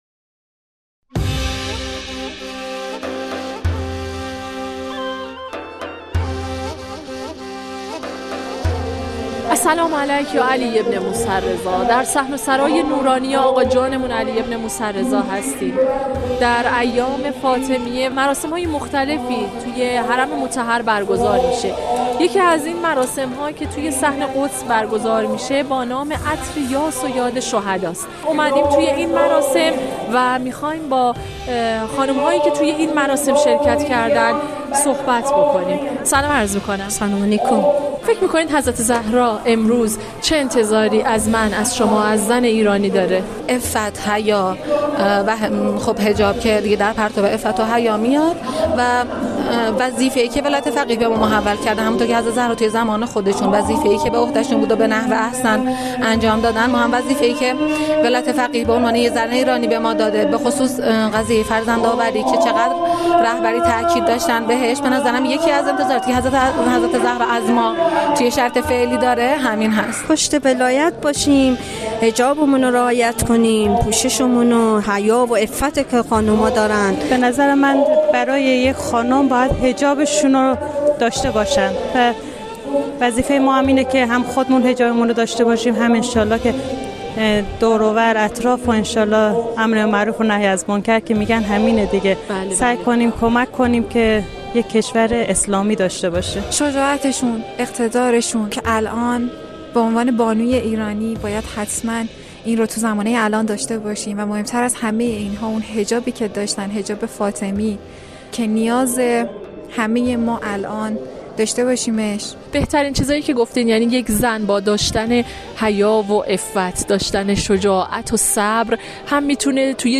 انتظارات حضرت زهرا سلام الله از زنان امروزی در نگاه زائران حرم مطهر رضوی/ گزارش رادیویی